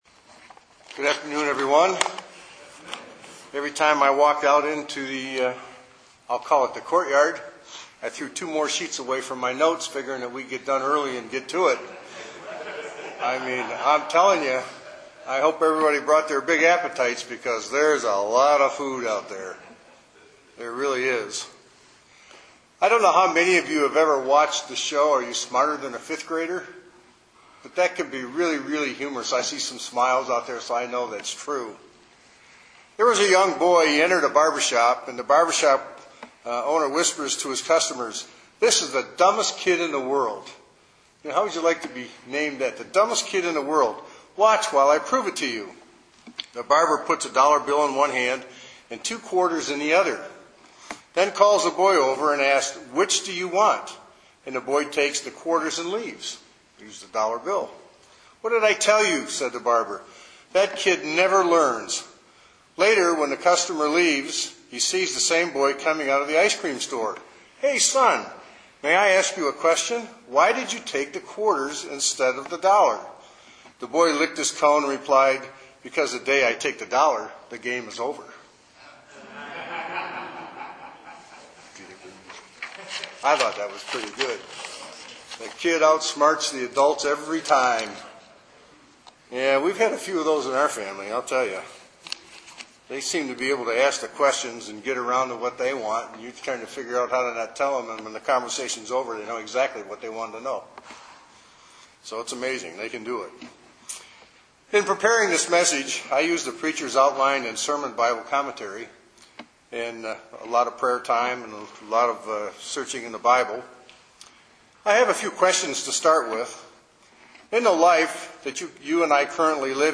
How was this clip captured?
Given in Detroit, MI